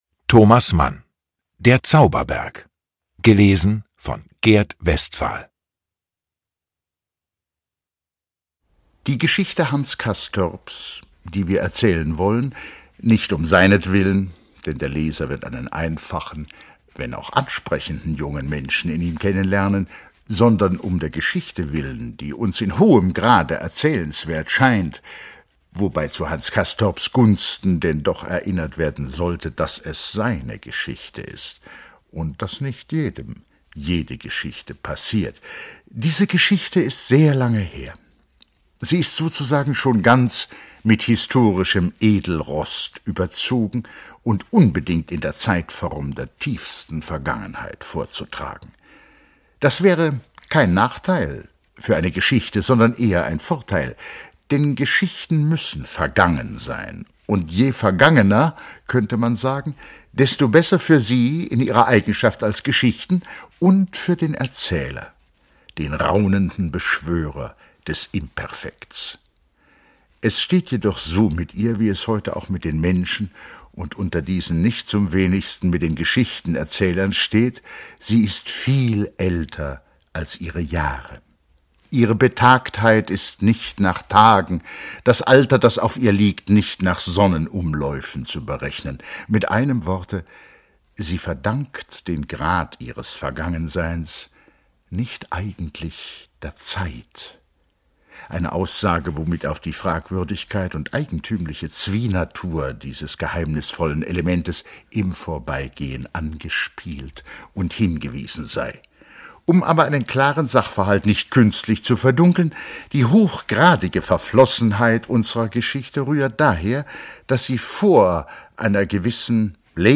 Lesung mit Gert Westphal (2 mp3-CDs)
Gert Westphal (Sprecher)